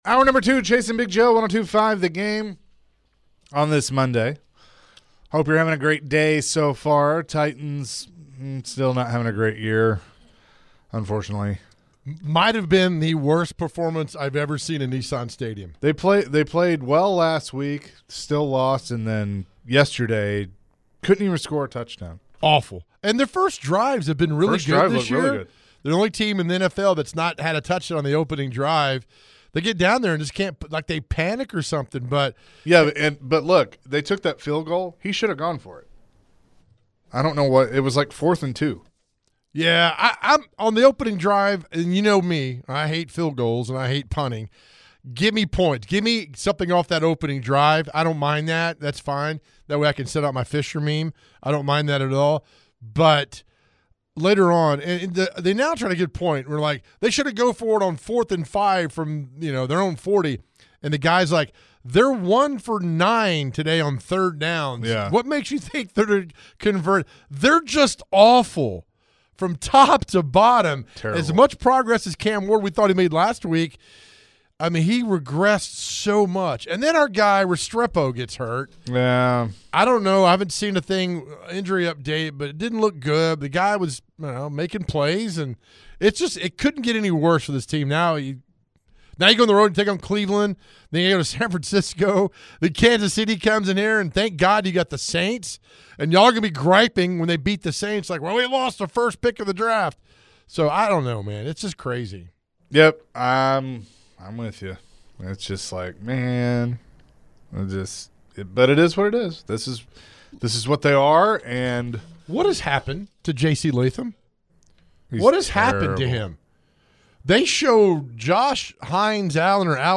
The guys speak with callers about the Titans.